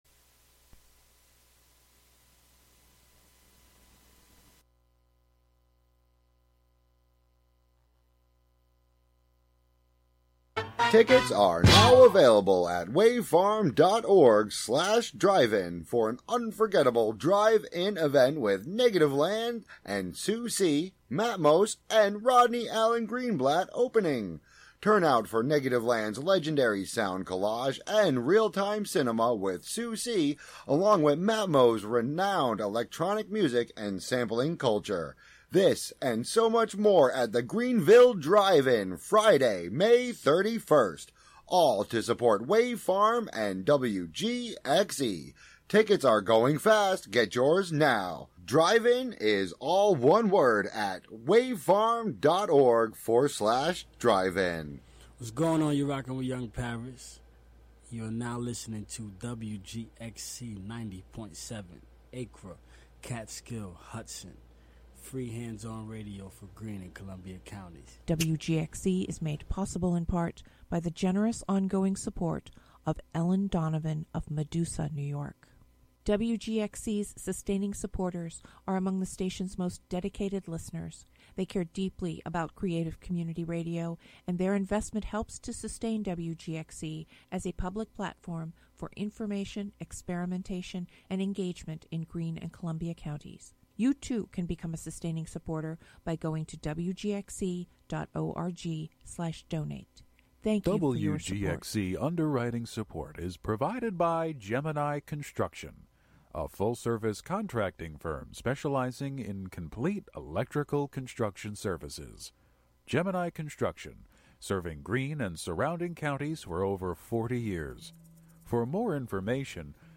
7pm Monthly program featuring music and interviews fro...
broadcast live from WGXC's Hudson studio.